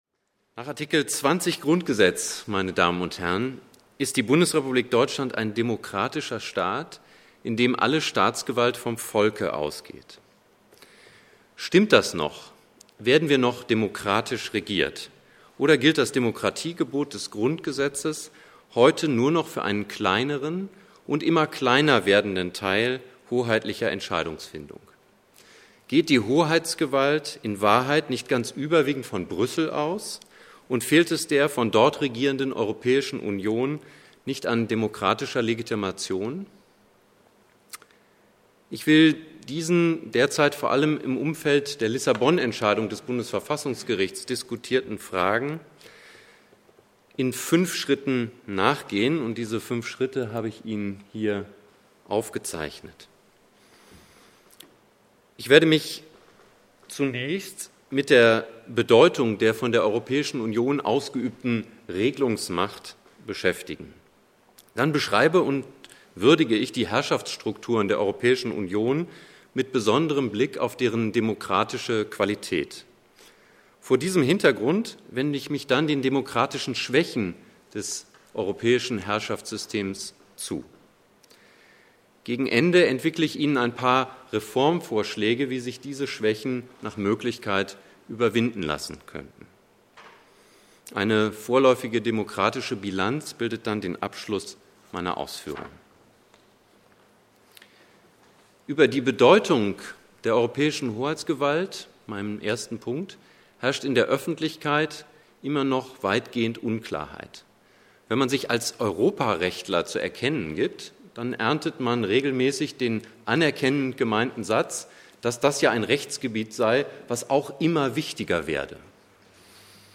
Geht die Hoheitsgewalt in Wahrheit nicht ganz überwiegend von Brüssel aus und fehlt es der von dort regierenden Europäischen Union nicht an demokratischer Legitimation? Der Vortrag geht diesen derzeit vor allem im Umfeld der Entscheidung des Bundesverfassungsgerichts zum EU-Reformver